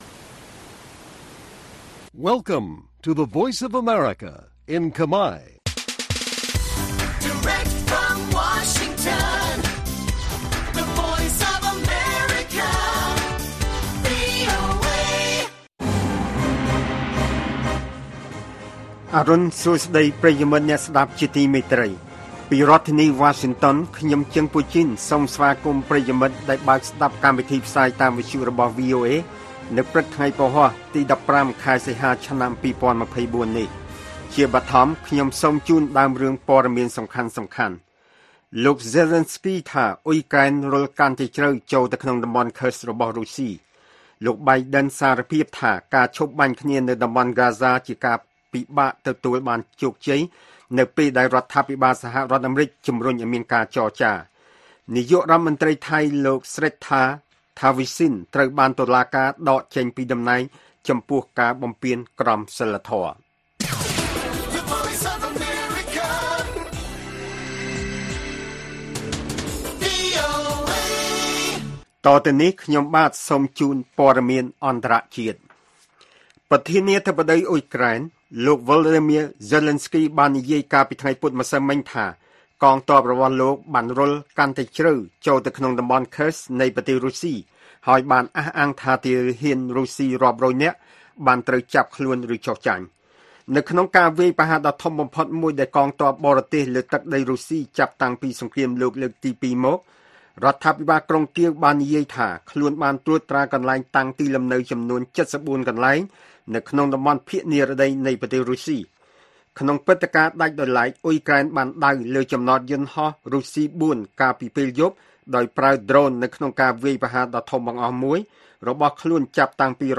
ព័ត៌មានពេលព្រឹក១៥ សីហា៖ លោក Zelenskyy ថាអ៊ុយក្រែន«រុលកាន់តែជ្រៅ»ចូលក្នុងតំបន់ Kursk របស់រុស្ស៊ី